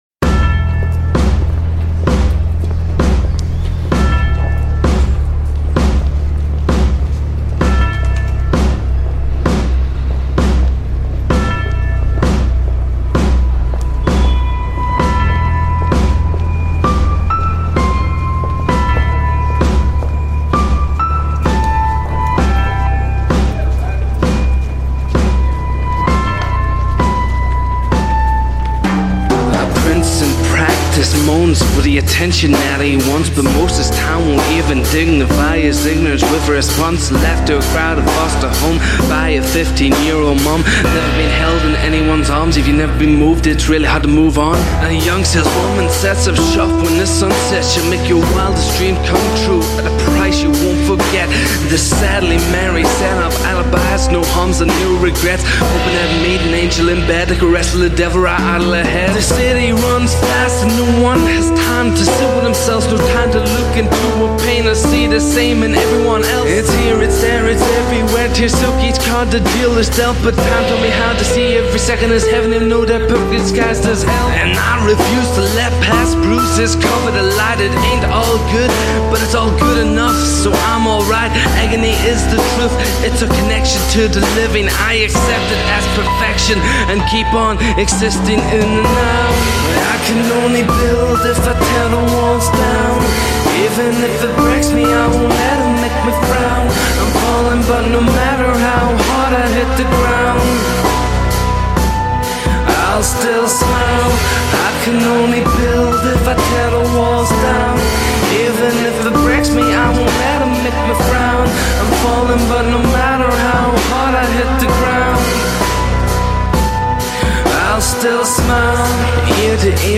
Soft Rap